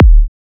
edm-kick-08.wav